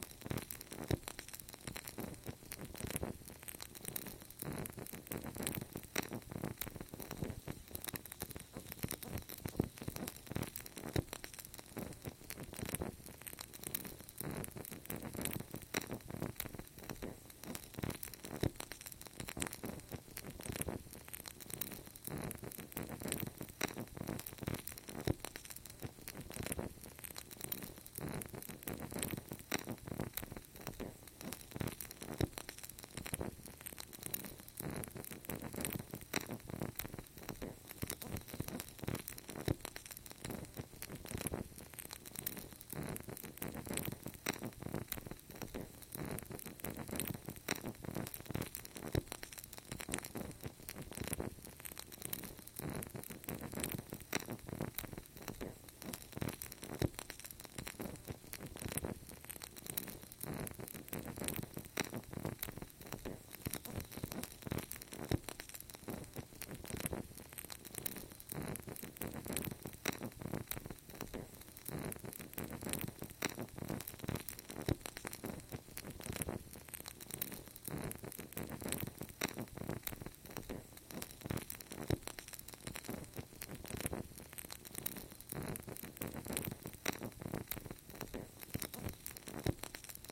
На этой странице собраны звуки горящих свечей – от тихого потрескивания до ровного горения.
Звуки пламени свечи: Долгий шум горящего огня